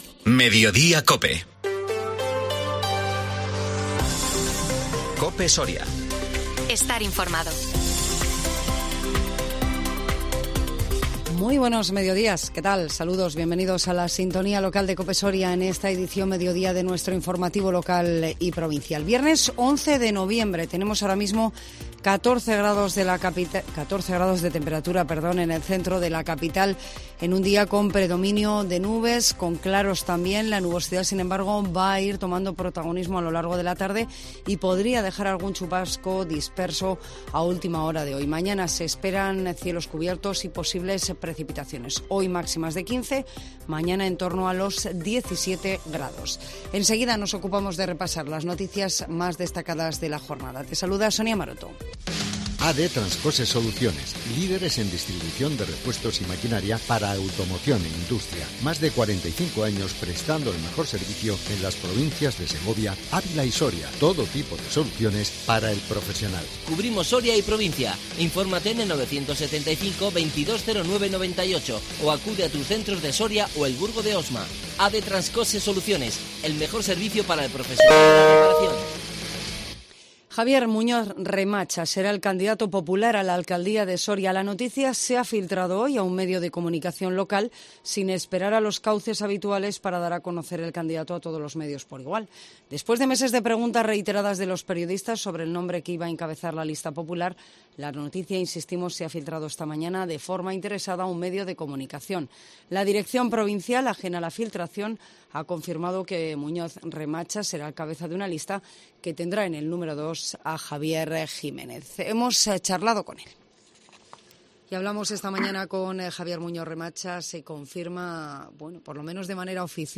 INFORMATIVO MEDIODÍA COPE SORIA 11 NOVIEMBRE 2022